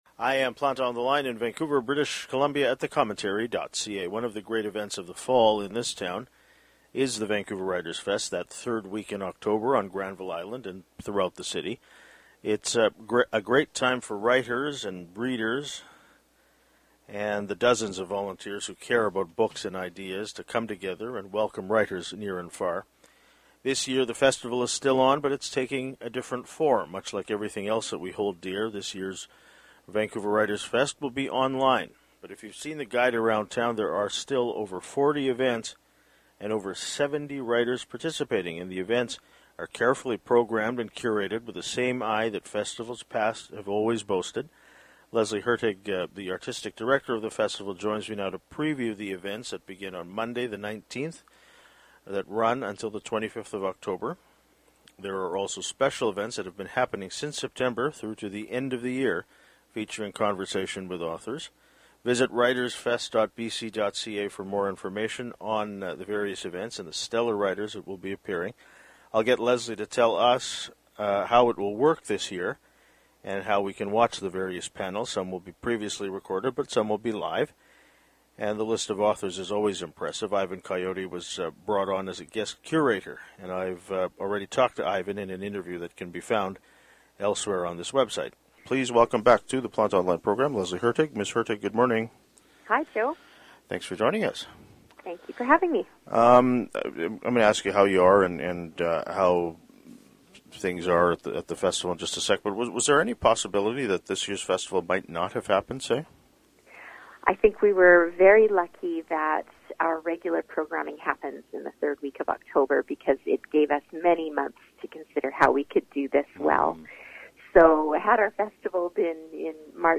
Ivan Coyote was brought on as a guest curator, and I’ve already talked to Ivan in an interview that can be found elsewhere on this website.